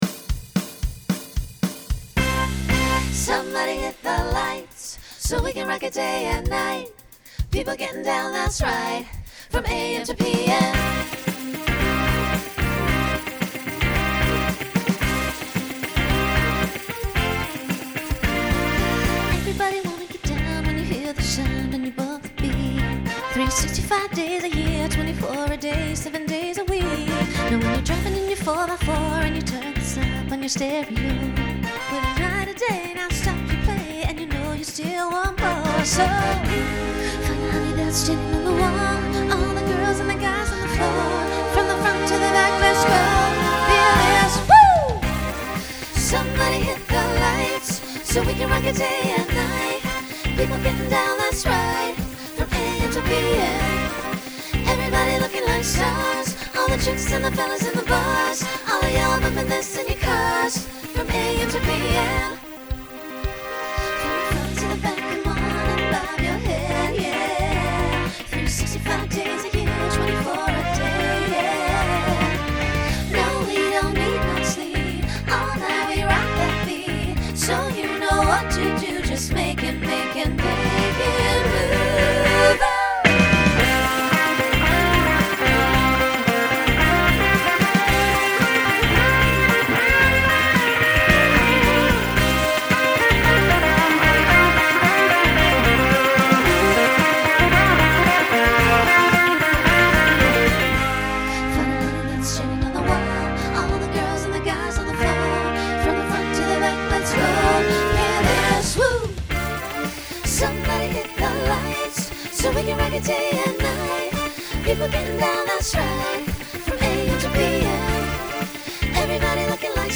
Genre Pop/Dance Instrumental combo
Transition Voicing SSA